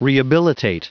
Prononciation du mot rehabilitate en anglais (fichier audio)
Prononciation du mot : rehabilitate